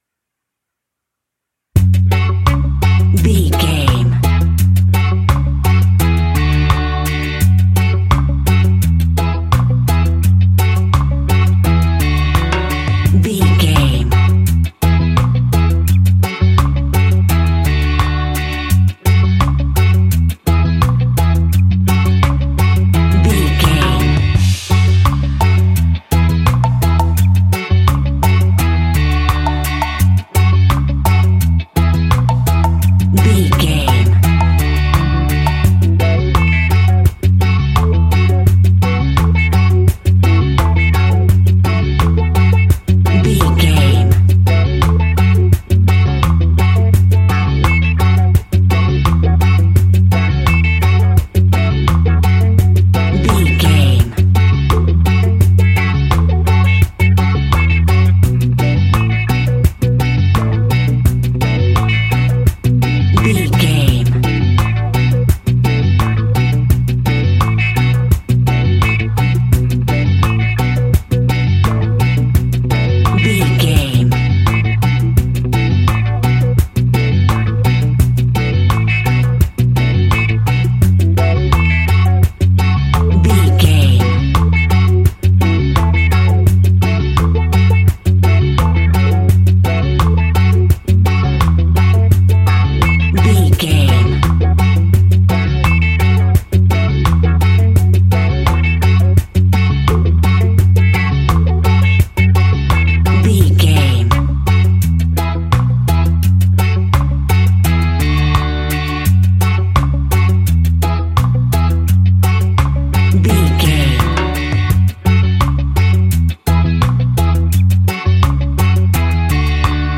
Classic reggae music with that skank bounce reggae feeling.
Aeolian/Minor
F#
laid back
chilled
off beat
drums
skank guitar
hammond organ
percussion
horns